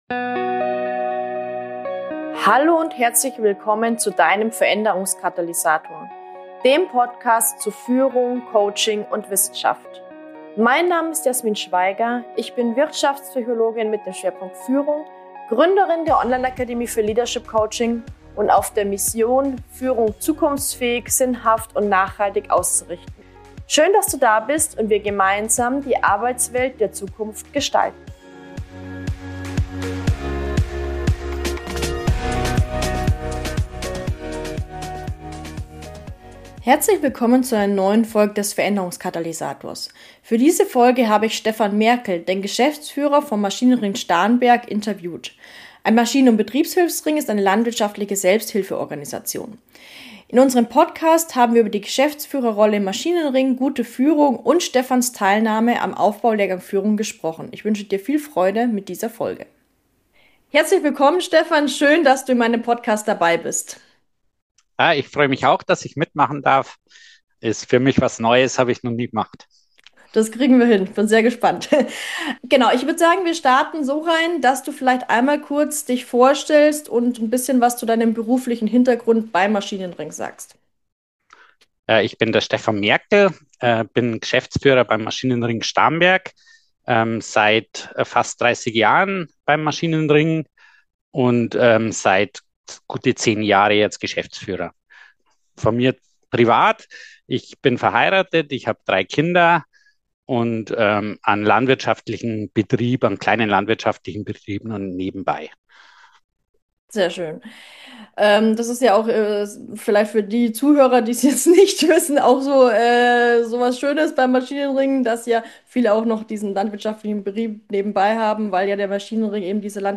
Geschäftsführung im Maschinenring - Interview